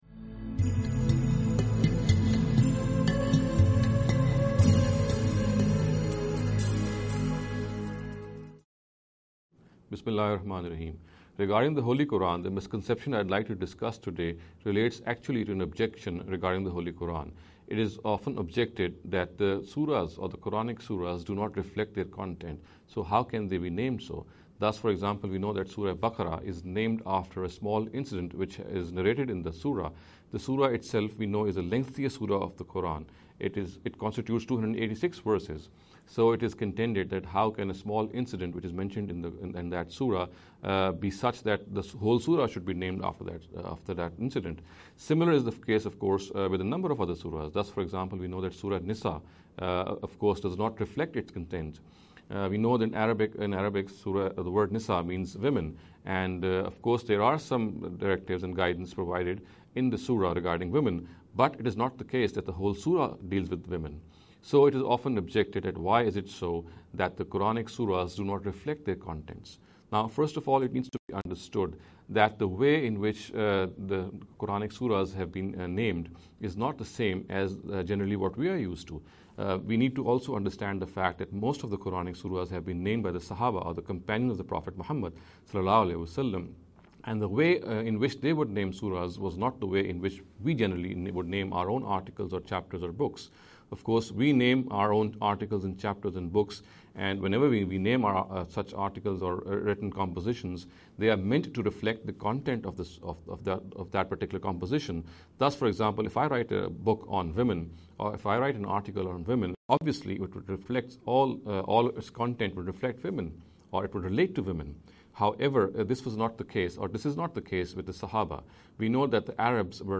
This lecture series will deal with some misconception regarding the Holy Qur’an. In every lecture he will be dealing with a question in a short and very concise manner.